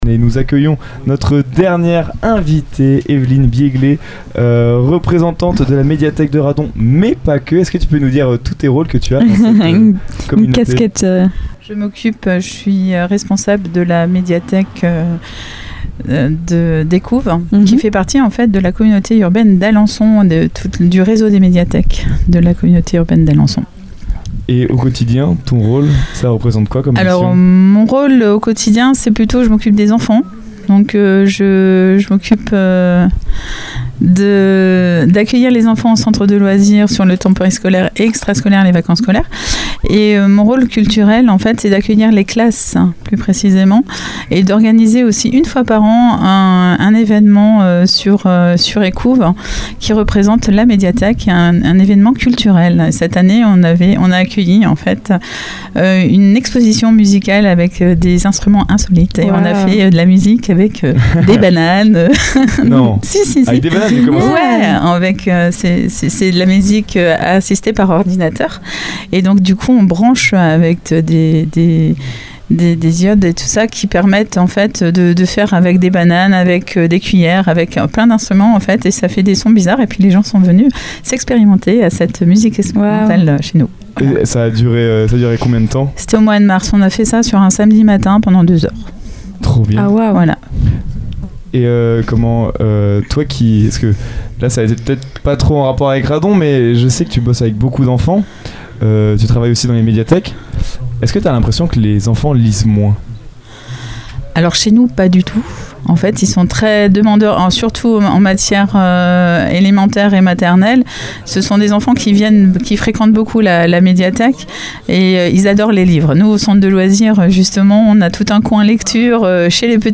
Lors du MysteryMachine Summer Tour, notre studiomobile s'est arrêté dans la commune d'Écouves, non loin d'Alençon, pour découvrir les secrets et histoires de ce village.